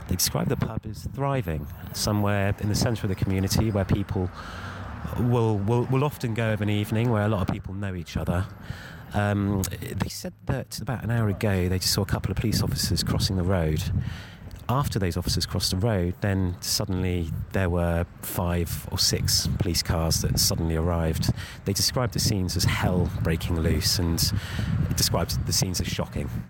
is at the scene of a reported shooting in Folkestone